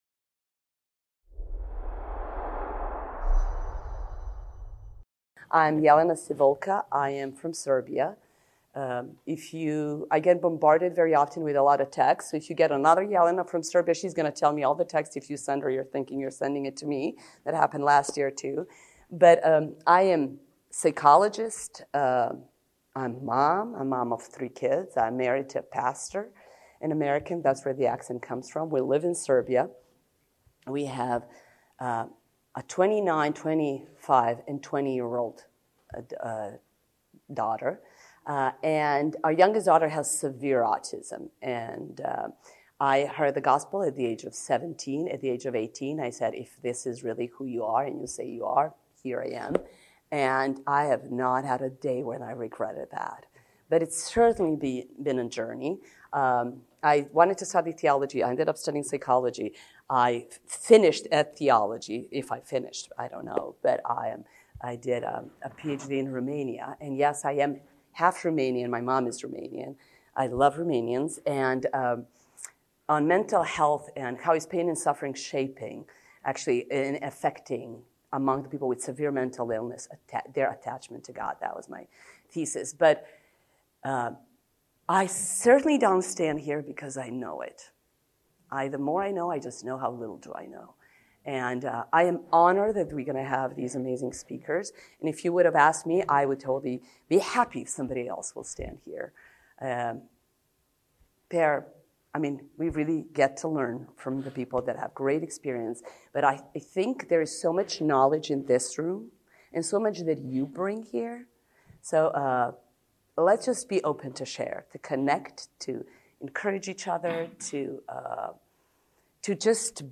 Event: ELF Pastoral Counsellors Network